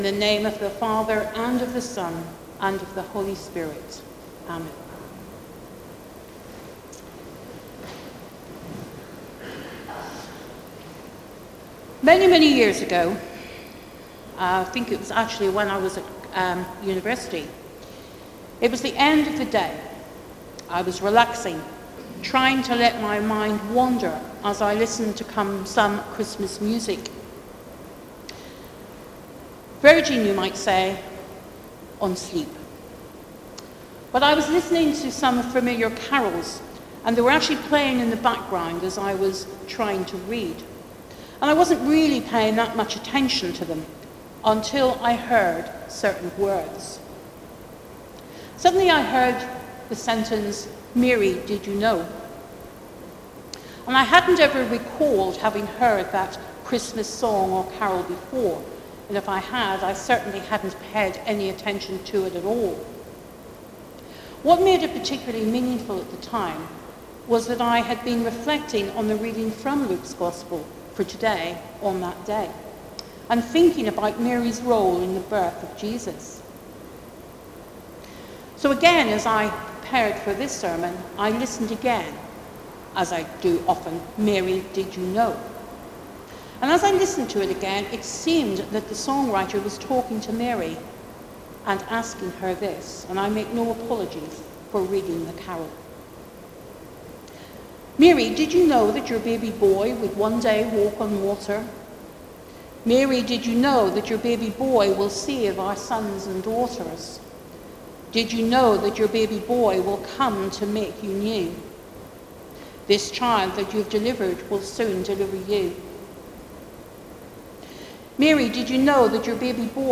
Sermon: Mary’s Challenge | St Paul + St Stephen Gloucester